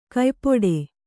♪ kaypoḍe